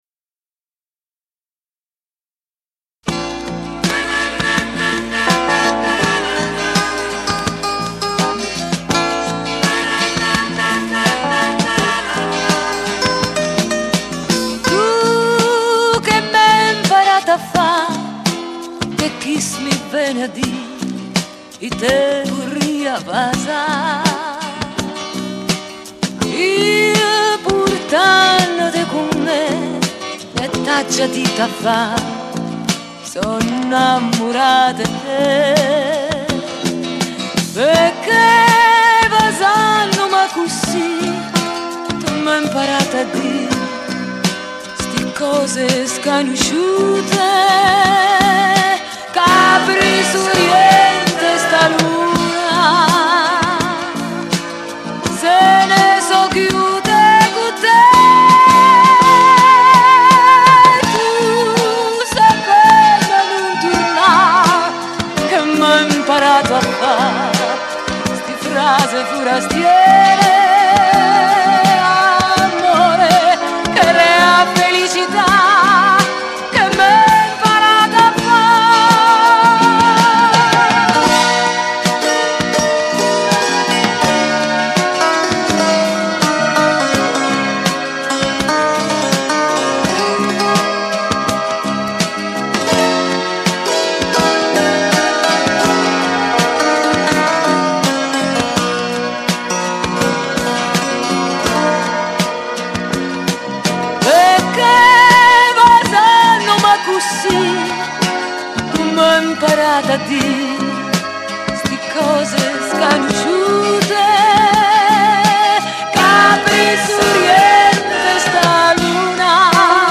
chitarra elettrica e dry guitar.
basso elettrico.
batteria e percussioni
tastiere.
mouth armonica.
Effetti elettronici